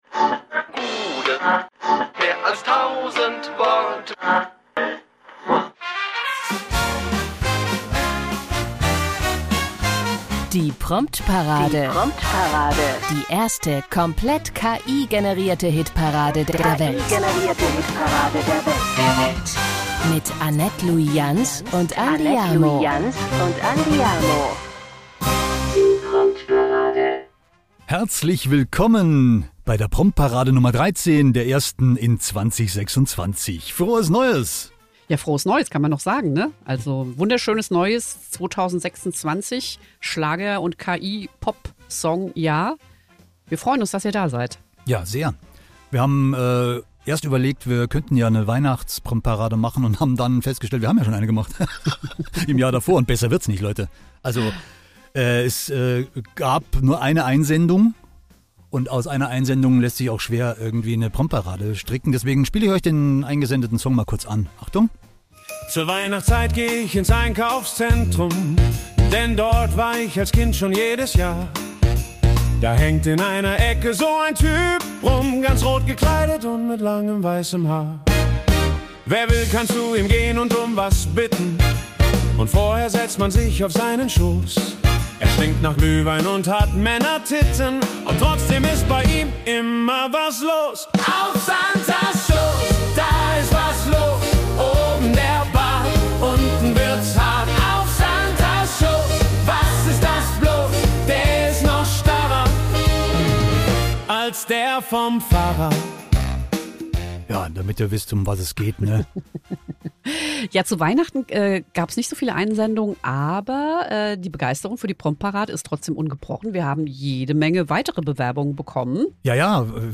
Nichts Neues: die weltweit erste KI-Hitparade hat sich erneut selbst übertroffen. Wie immer stammt alles aus dem digitalen Hirn unserer Lieblingsmaschinen. Kein Instrument, keine Stimme, kein echter Ton – alles künstlich, alles unecht und echt absurd.